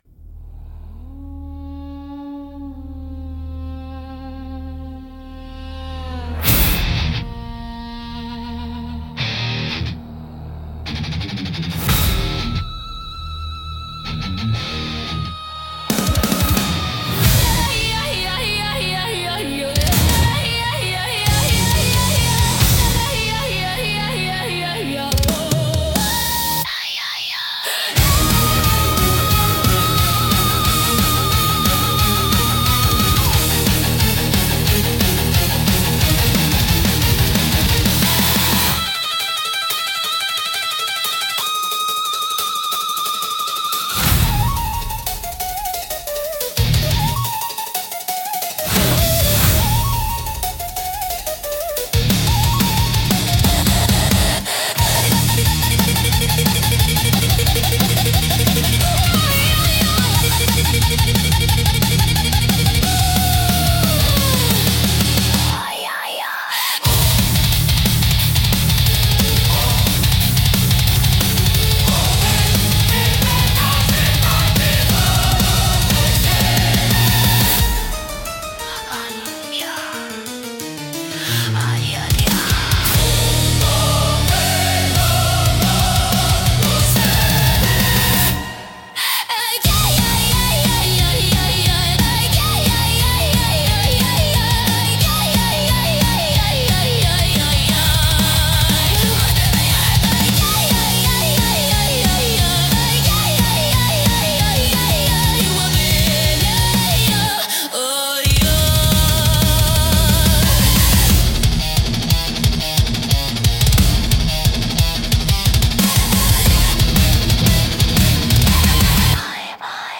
熱狂的で迫力あるサウンドが勝負や決戦の場面を盛り上げ、プレイヤーや視聴者の集中力と興奮を引き出します。